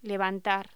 Locución: Levantar